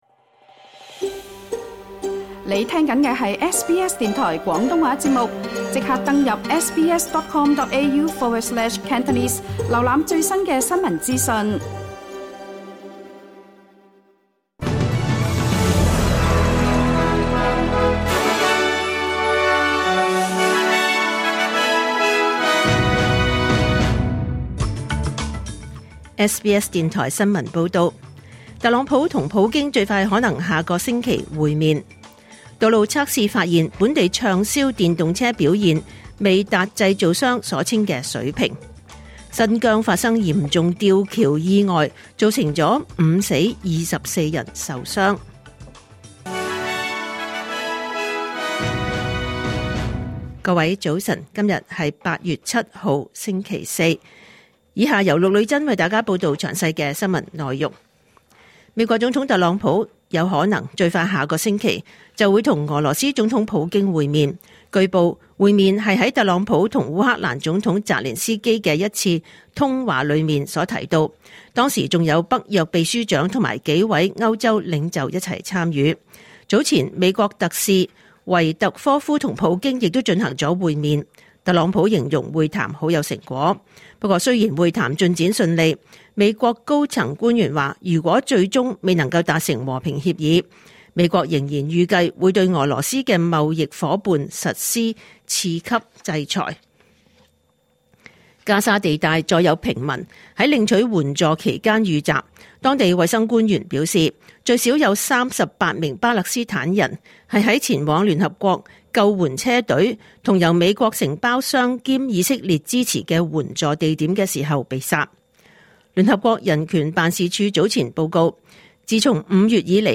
2025年8月7日SBS廣東話節目九點半新聞報道。